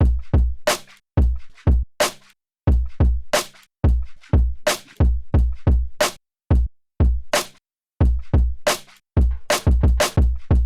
drums1